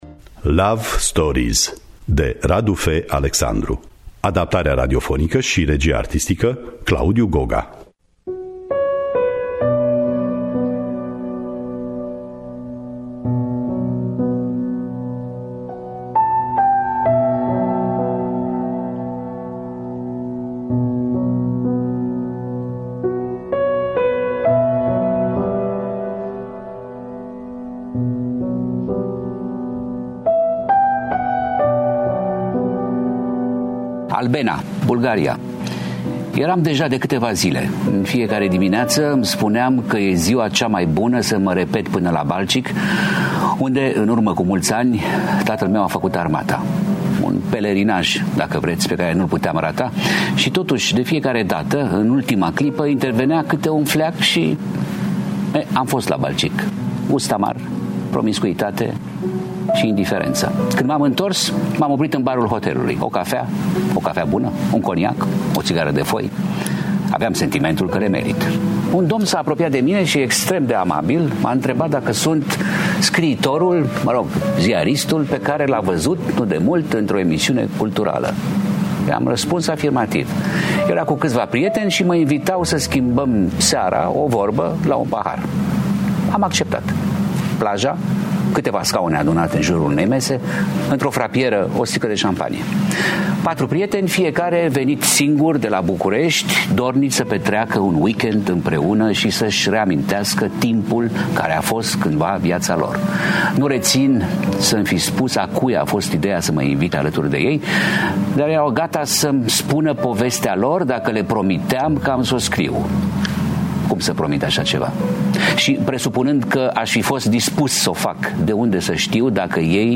Adaptarea radiofonică
Cu participarea lui Emil Hurezeanu.